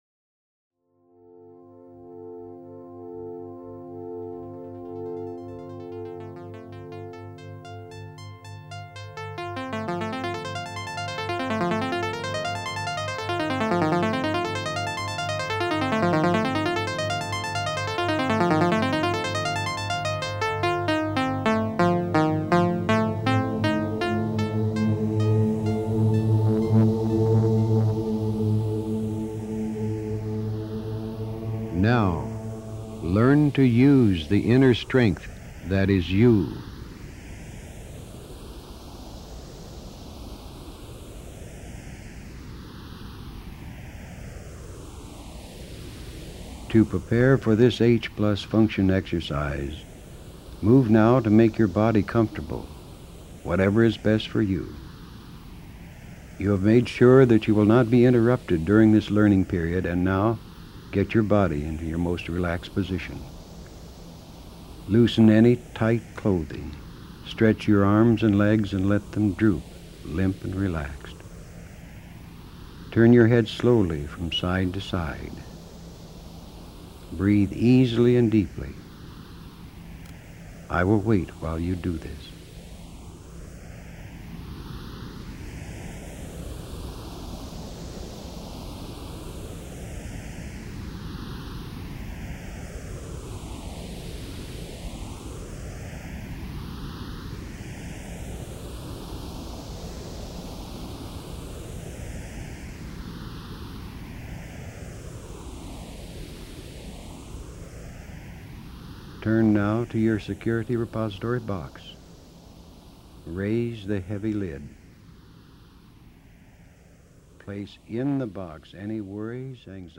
(Human Plus – verbally guided – 60 min.)